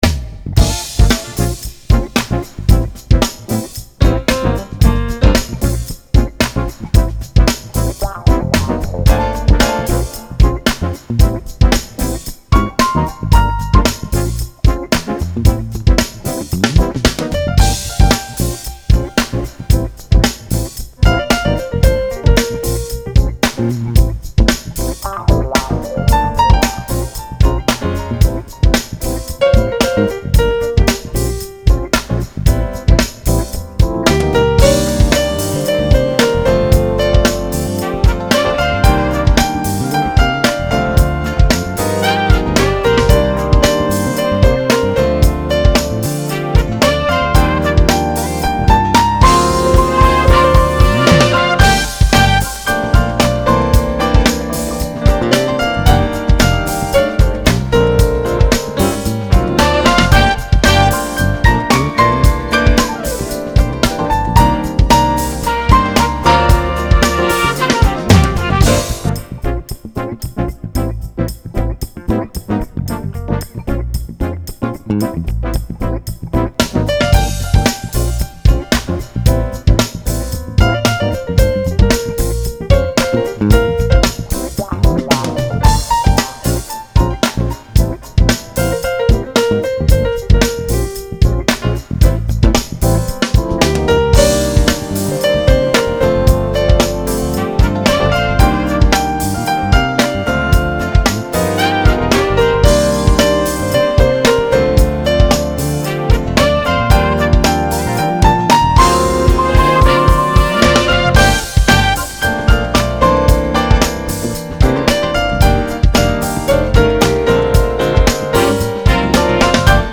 Пианист
crossover jazz, contemporary jazz и new age